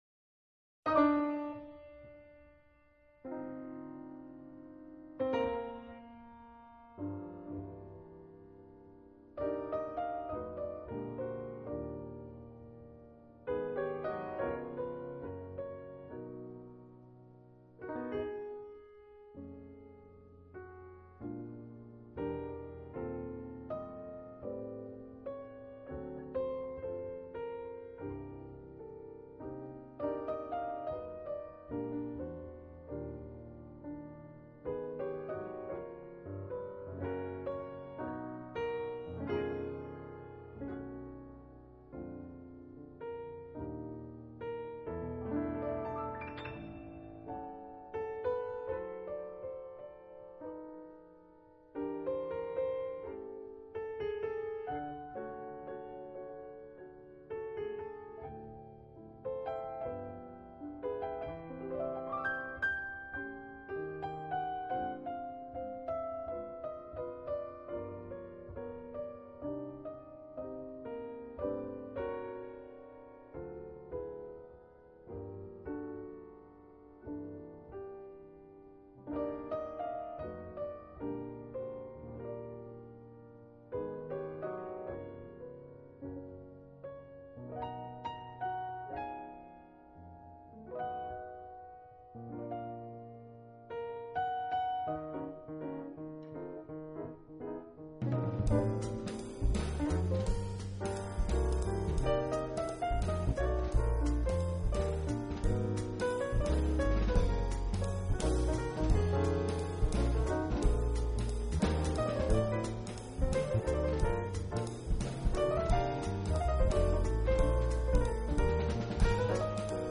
很少有這樣的組合：爸爸彈鋼琴，帶著四個親兒子分別演奏電貝斯、大提琴、
之處，就在於正確的音像形體大小，以及自然浮凸的音場表現。
相當自然，音質與透明感俱佳，您所需要注意的只是音樂類型的喜好問題。